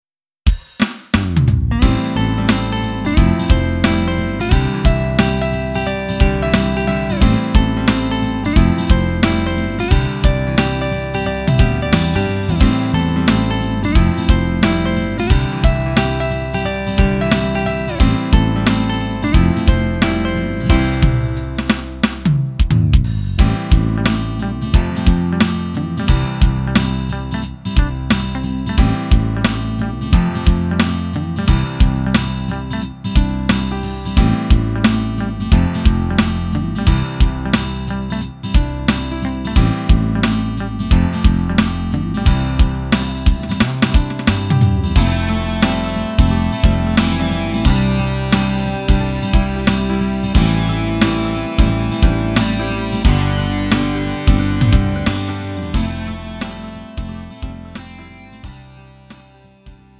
음정 -1키 4:43
장르 구분 Lite MR